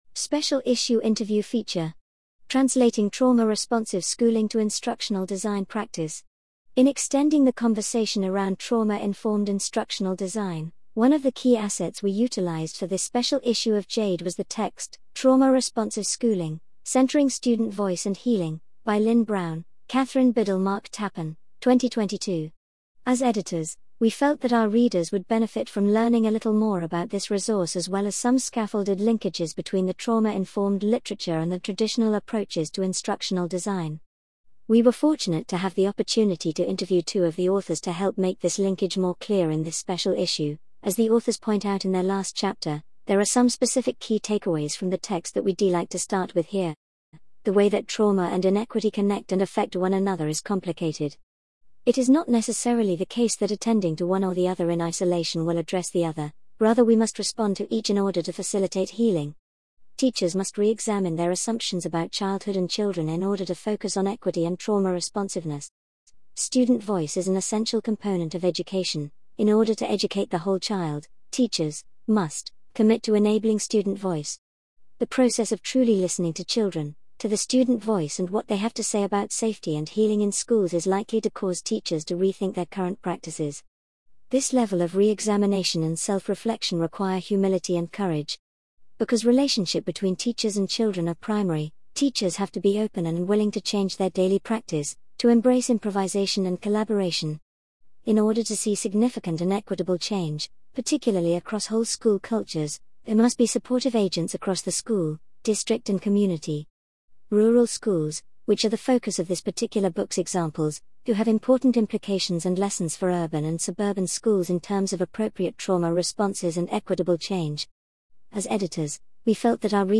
Special Issue Interview Feature: Translating Trauma-Responsive Schooling to Instructional Design Practice
Below is an edited and focused interview between the guest editors of this special issue (JAID) and the authors of the text (AU):